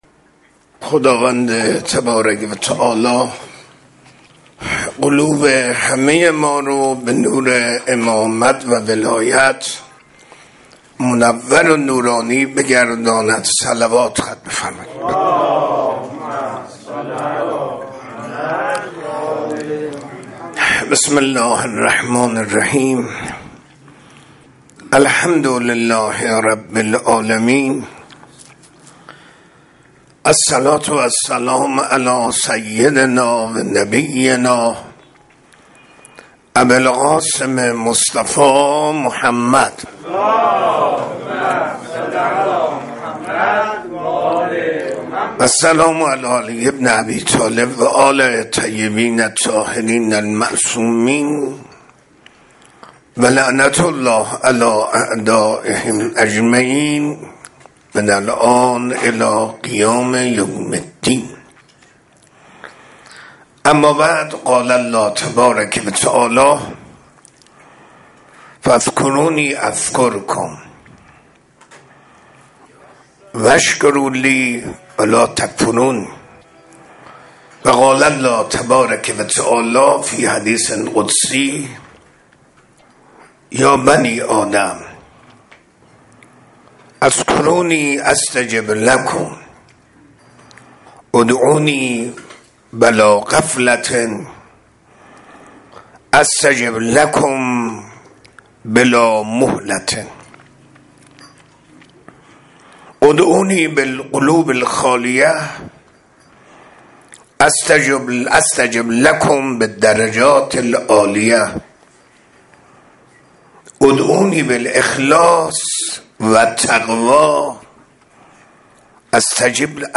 منبر 7 مرداد 1403